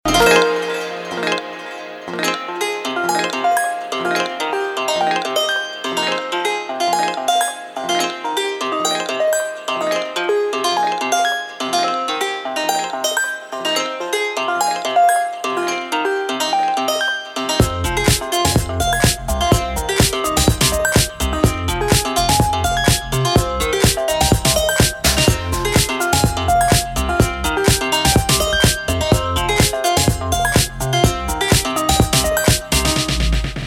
• Качество: 224, Stereo
гитара
Tech House
латинские